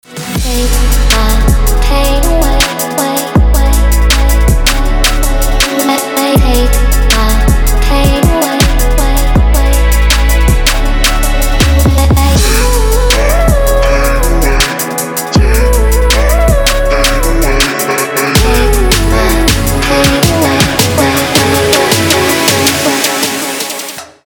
• Качество: 320, Stereo
Electronic
EDM
chillout
Trap